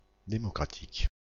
Ääntäminen
Synonyymit républicain démocrate antifasciste Ääntäminen (France): IPA: /de.mɔ.kʁa.tik/ Haettu sana löytyi näillä lähdekielillä: ranska Käännöksiä ei löytynyt valitulle kohdekielelle.